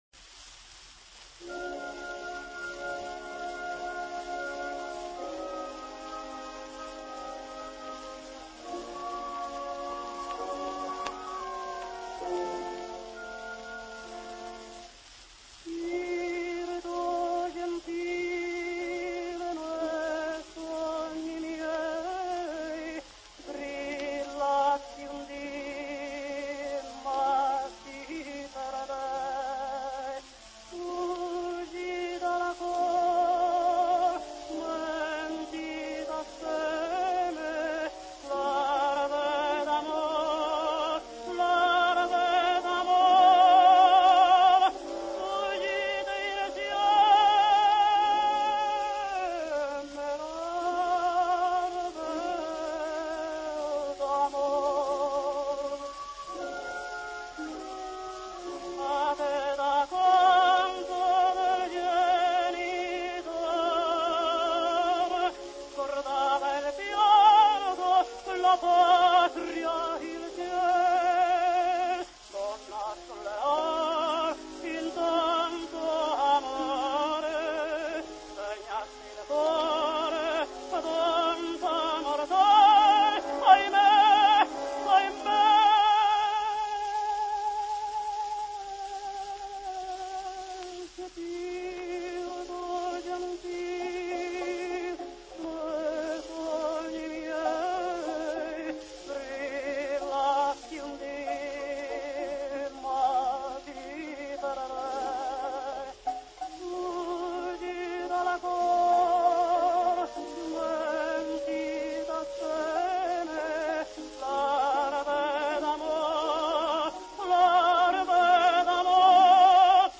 Mexican tenor.